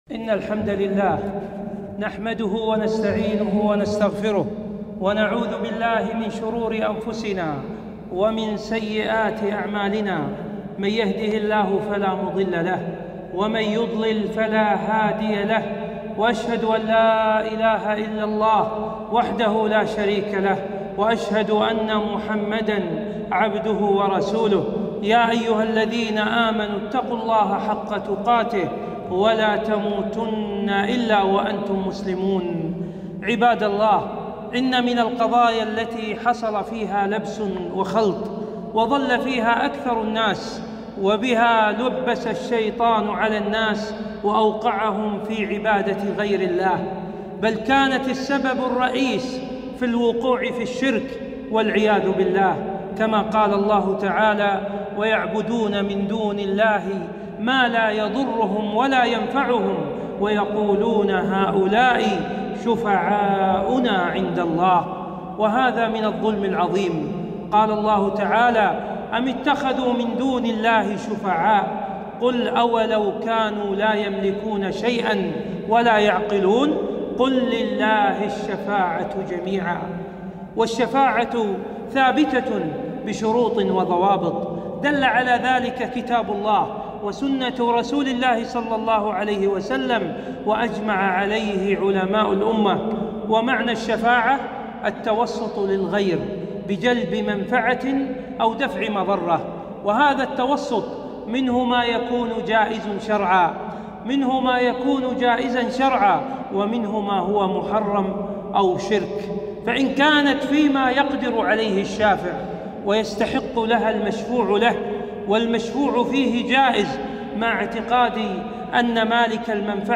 خطبة - أقسام الشفاعة وشروطها